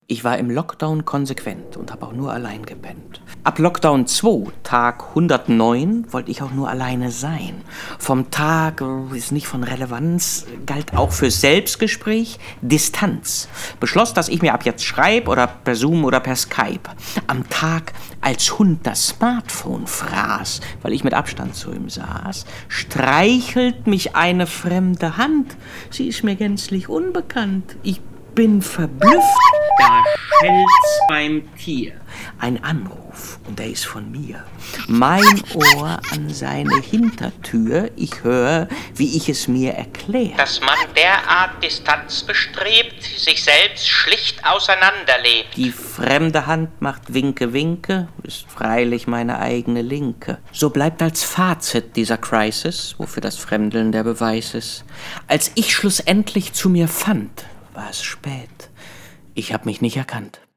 Hier nun also das Hörstückchen, mit dem ich zum 12. Berliner Hörspielfestival eingeladen wurde. Die Regeln für den Wettbewerb „Mikroflitzer“: Hörspiel, max. 60 sec, der Satz „Ich habe mich nicht erkannt“ und das Geräusch eines Tieres, das einen Klingelton imitiert, müssen enthalten sein.